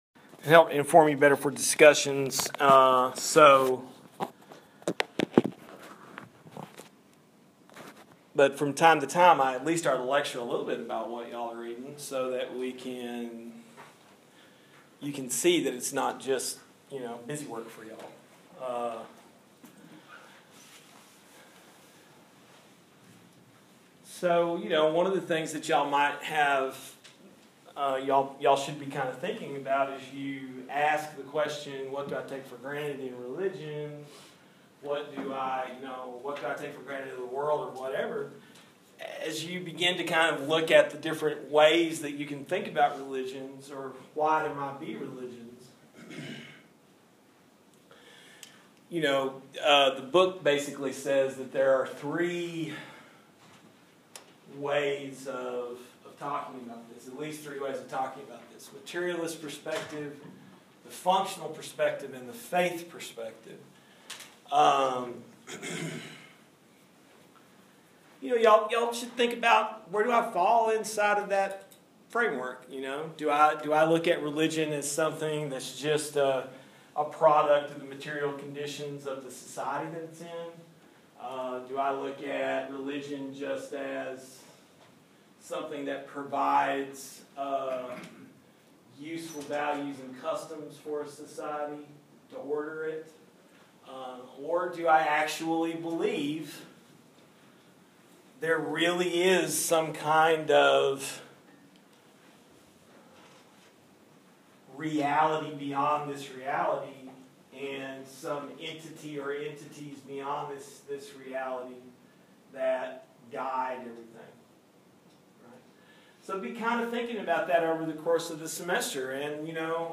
Below my lecture from 13 July 2016.